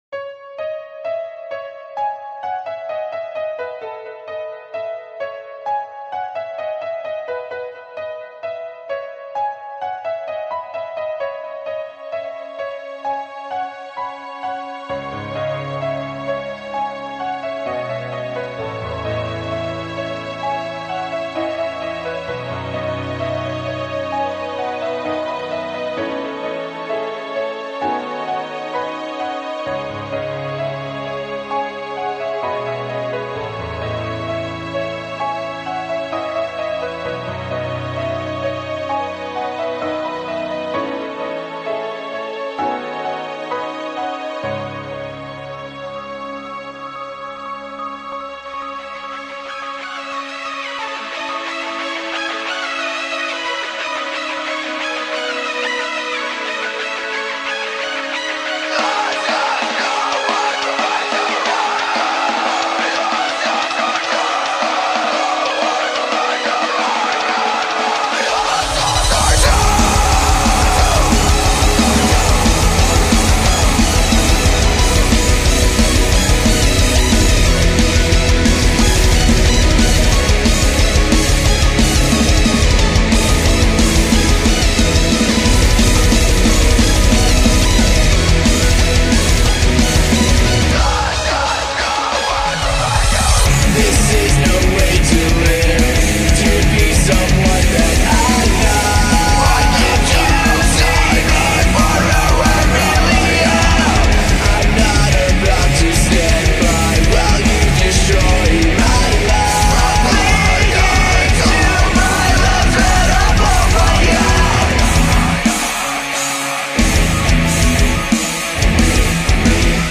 I luvz u guyz!! xD Fluttershy song!!!